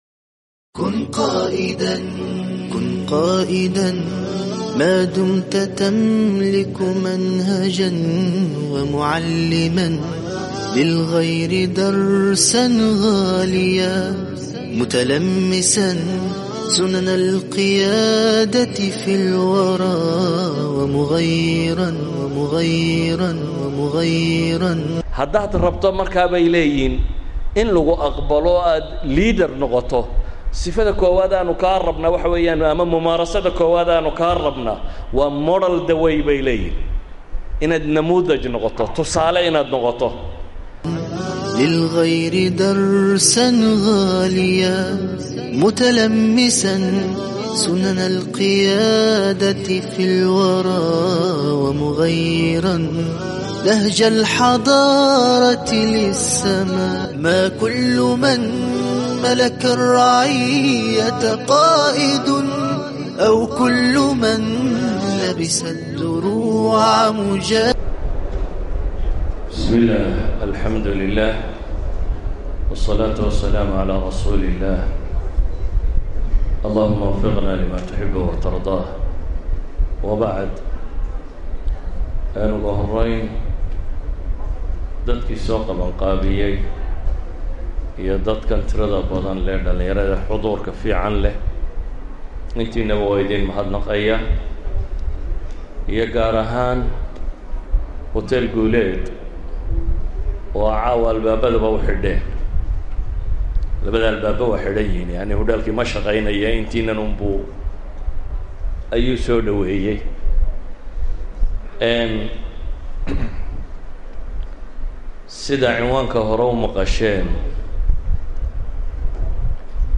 Genre: Muxaadaro.